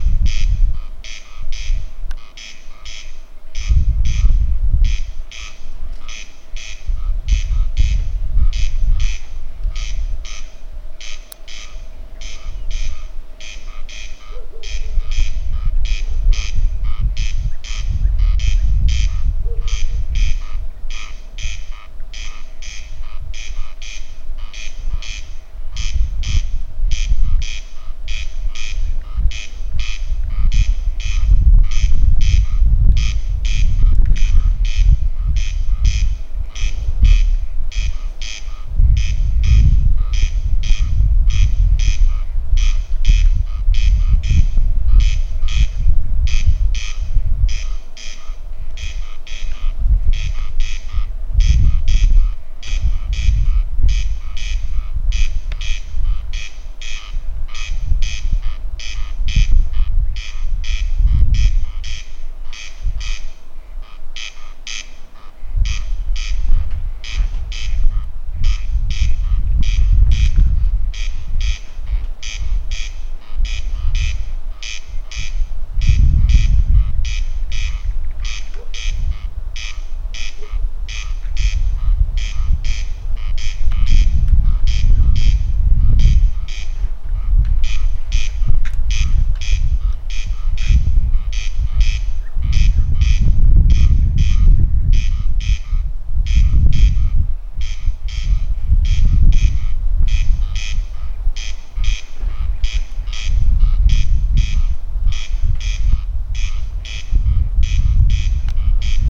Grieze, Crex crex
Administratīvā teritorijaOlaines novads
Dzirdama no dažādām lauka vietām, kur ir lauksaimniecības zeme ar garu, nepļautu zāli.